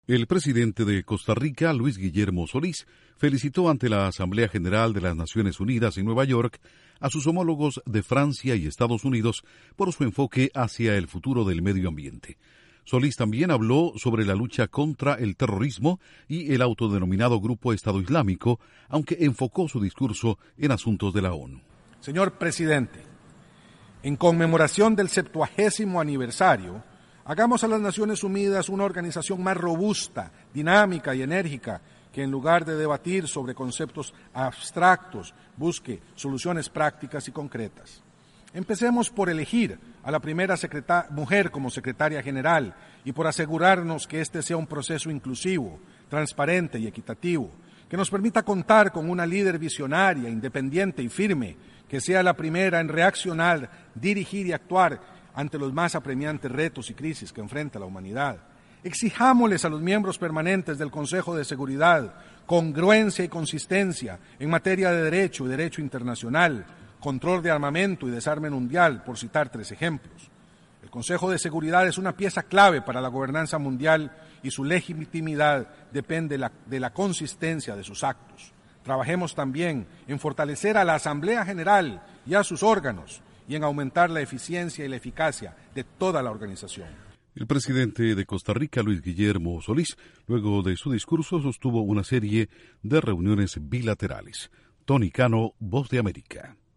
El Presidente de Costa Rica, Luis Guillermo Solís, pide ante las Naciones Unidas que se nombre a una secretaria general en ese organismo mundial. Informa desde la Voz de América